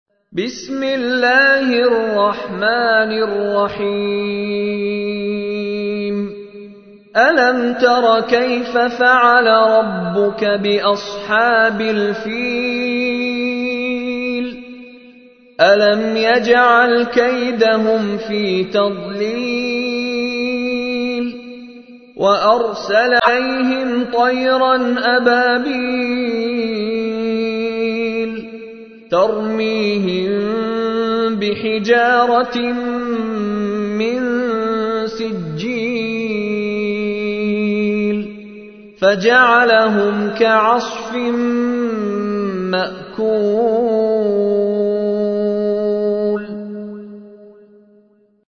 تحميل : 105. سورة الفيل / القارئ مشاري راشد العفاسي / القرآن الكريم / موقع يا حسين